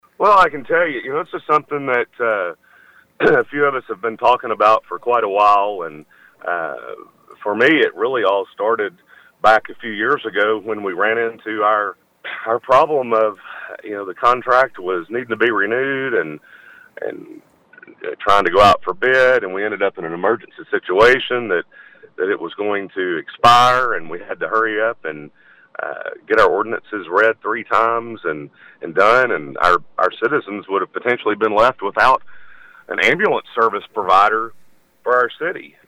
Win Trafford, who is chairman of the Public Safety Committee appeared on the 101.3 morning show Thursday and explained why the subject came up.